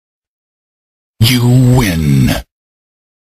Download Tekken you win sound effect for free.